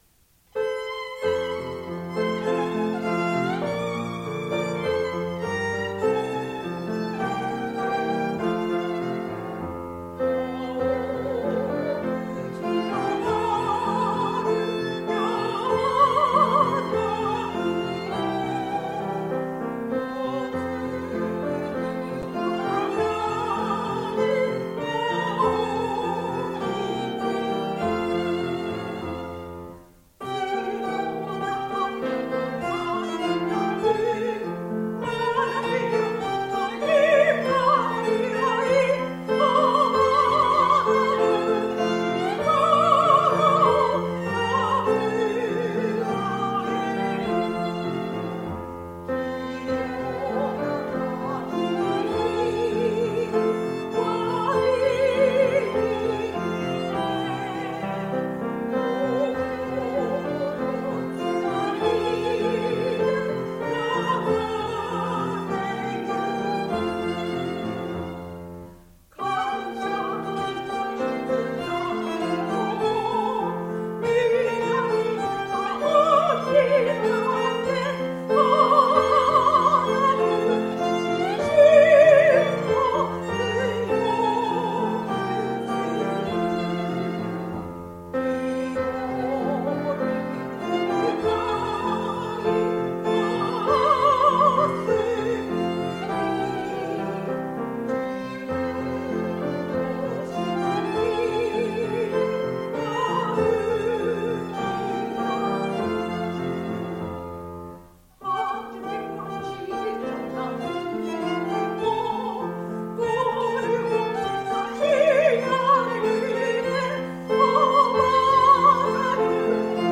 校 歌 本校の校歌が完成しました！